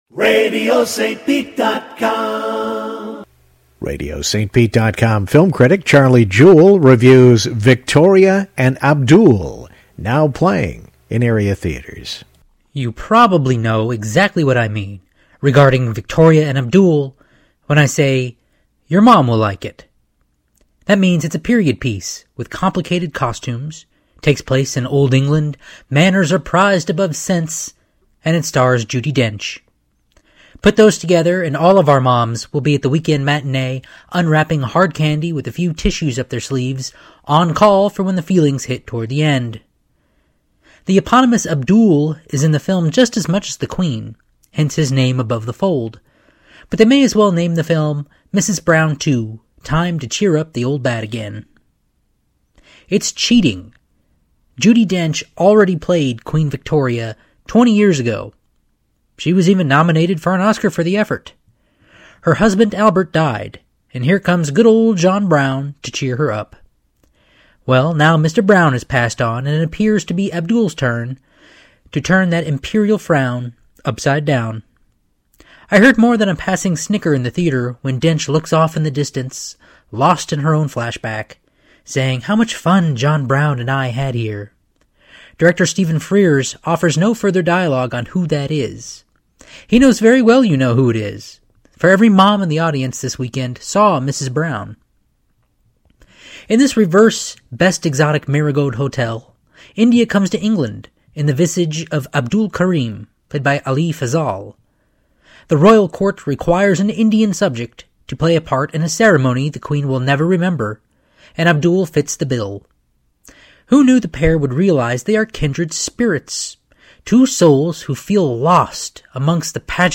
"Victoria and Abdul" Film Review